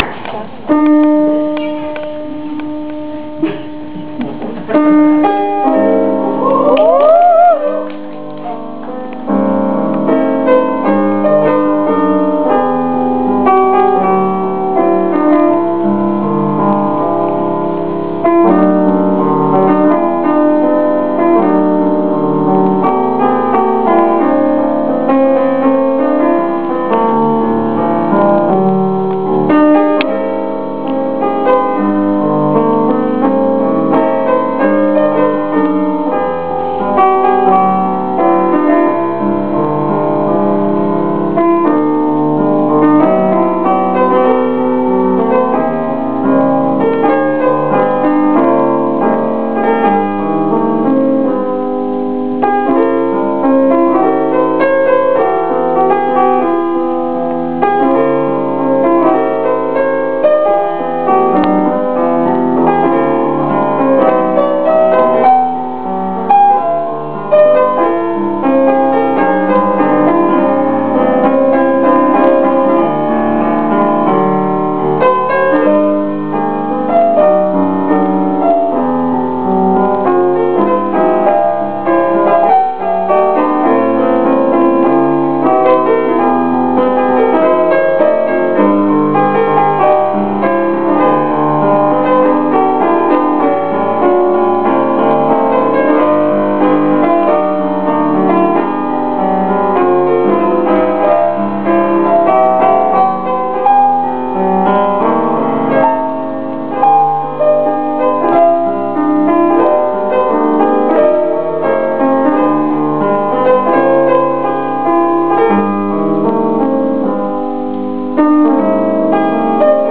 개인적인 생각이지만 아무래도 시간이 늦어질까봐 일부러 템포를 올리신것 같습니다;;
상당히 빠르네요 ;; 원곡보다 1.5배정도 ;ㅅ;